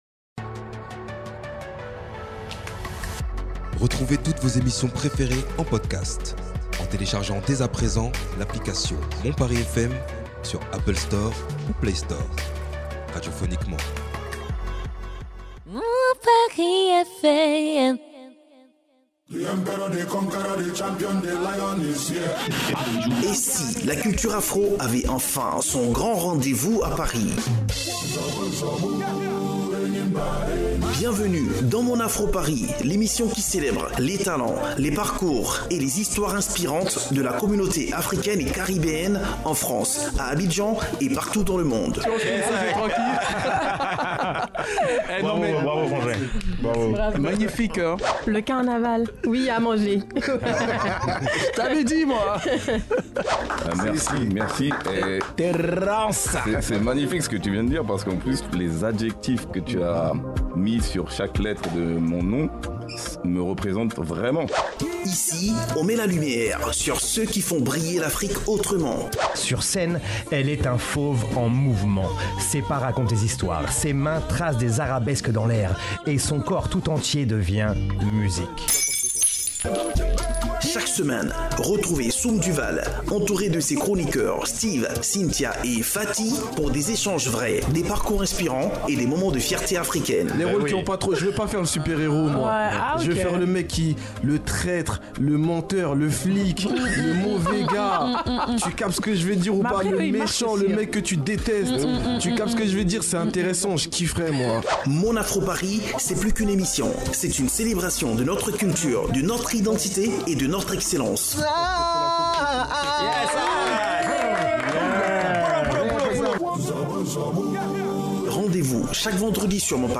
Plongez dans un échange passionnant autour du slam,de la poésie urbaine et d’un projet international qui donne une voix à toute une génération.